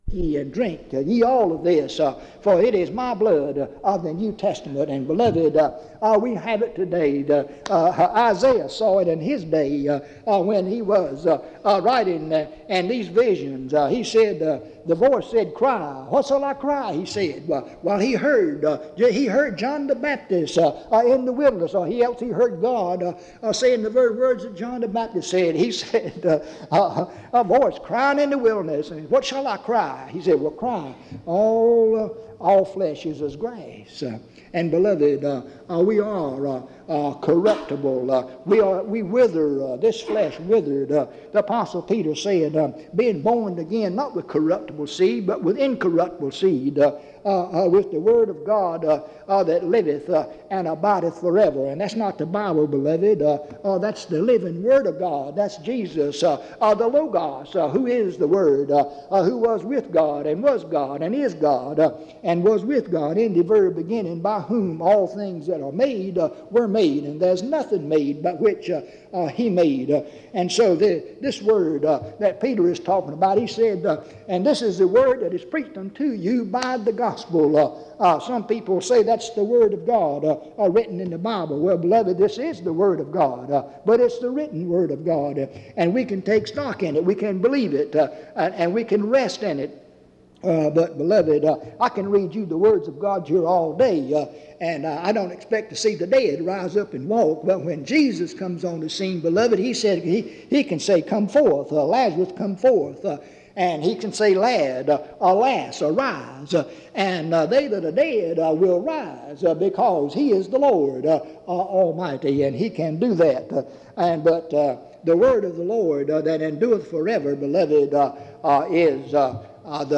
Dans Collection: Reidsville/Lindsey Street Primitive Baptist Church audio recordings La vignette Titre Date de téléchargement Visibilité actes PBHLA-ACC.001_019-A-01.wav 2026-02-12 Télécharger PBHLA-ACC.001_019-B-01.wav 2026-02-12 Télécharger